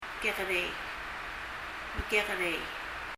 kekerei　　[kɛgərɛi]
複数形　：　　mekekerei　　[məkɛgərɛi]
と発音されるはずで、聴いてみるとたしかに、二番目の k は　[g]音のようです。
mekekerei　　[məkɛgərɛi]